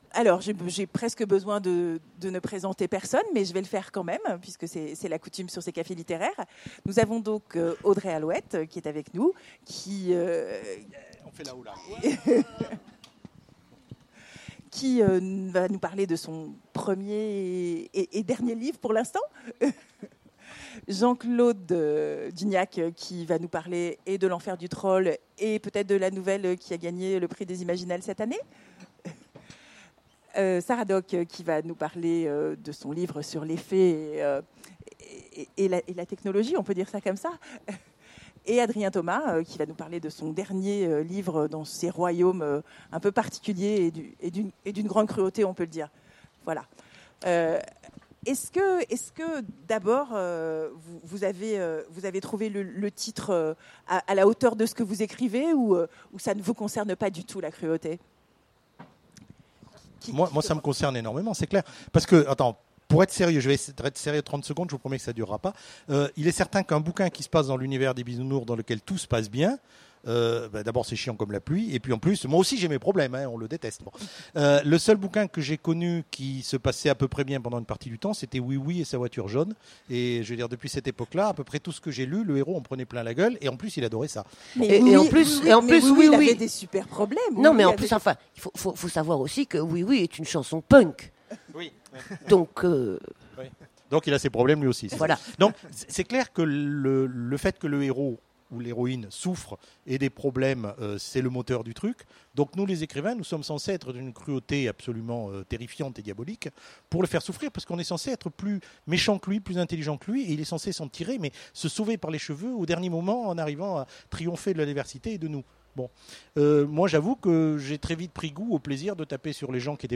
Mots-clés Fantasy Conférence Partager cet article